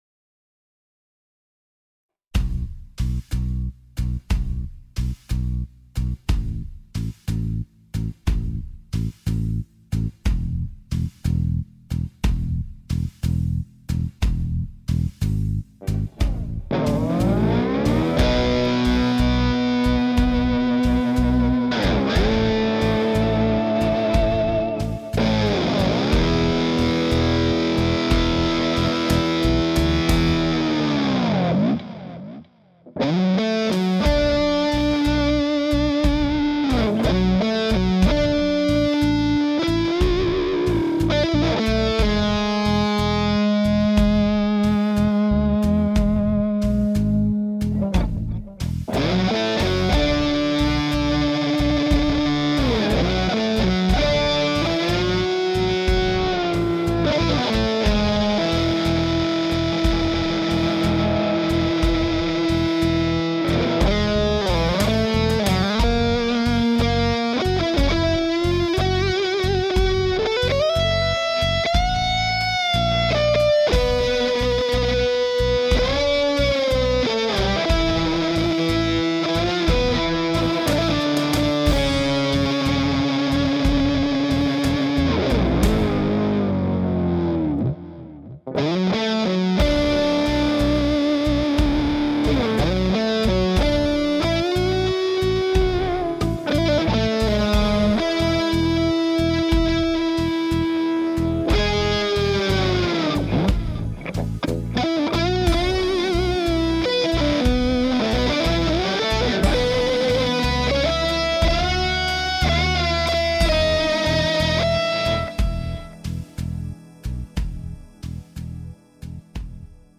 EH Pitchfork pedal demo
It tracks perfectly clean and has a bazillion sounds